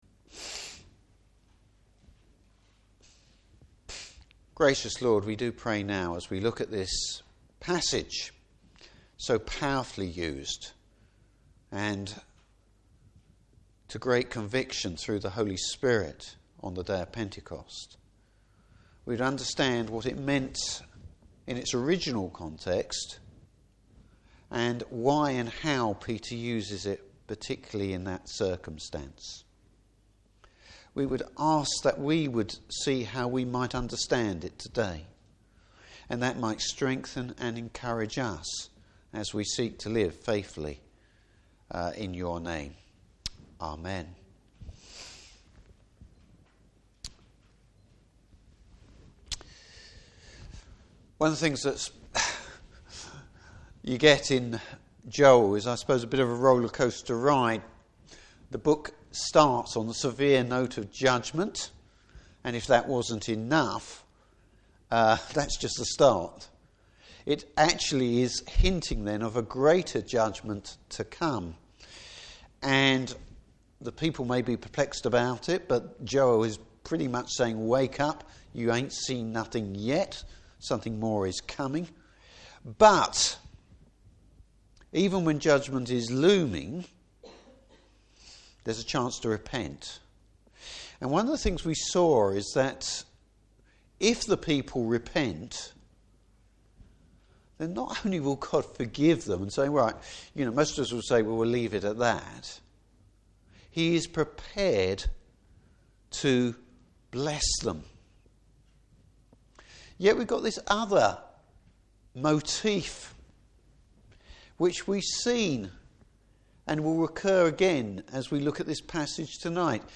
Passage: Joel 2:28-32. Service Type: Evening Service Even more blessing!